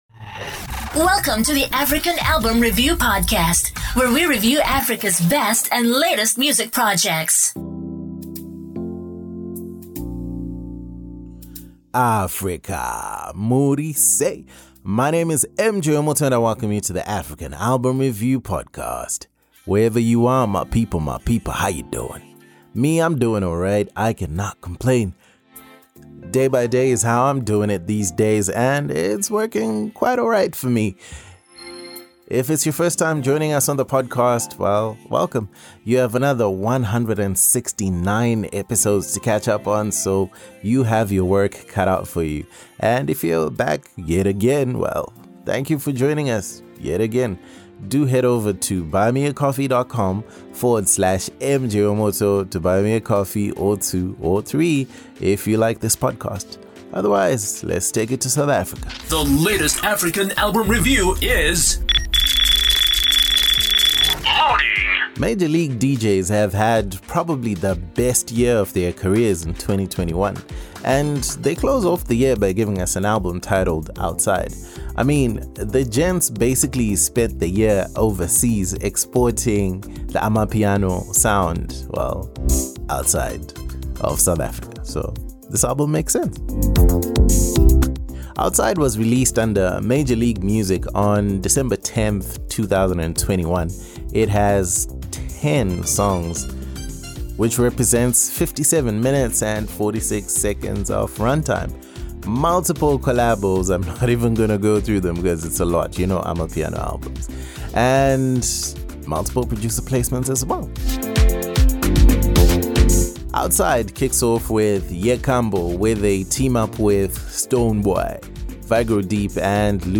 Major League DJz – Outside ALBUM REVIEW South Africa